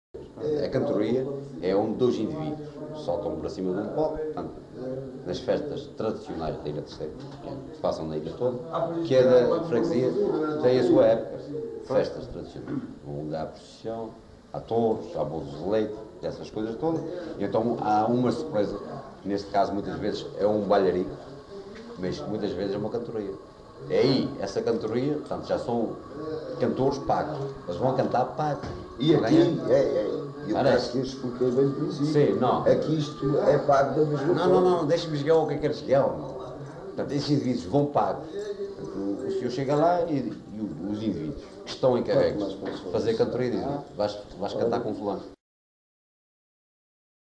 LocalidadeFontinhas (Praia da Vitória, Angra do Heroísmo)